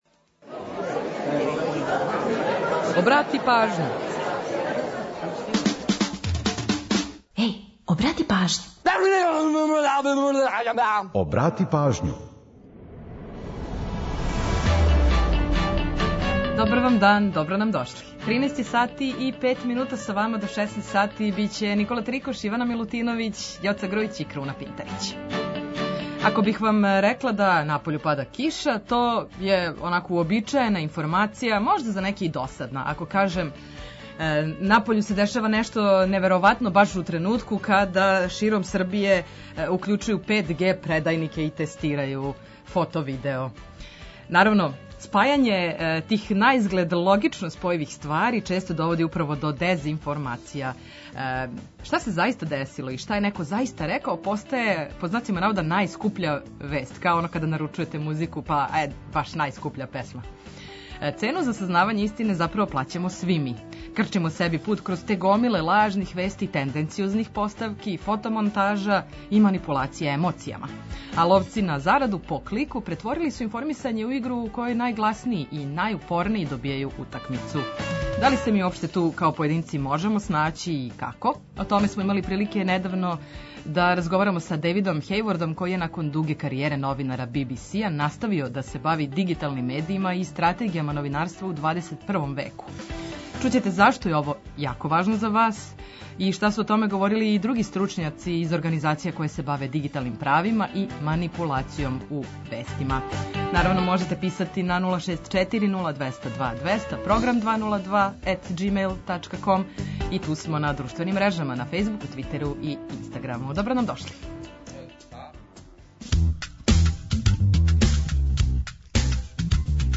Присећамо се важних догађаја из света музике, ту су и пола сата музике само из Србије и региона, новитети са топ листа, приче о песмама и наш репортер са актуелним градским информацијама.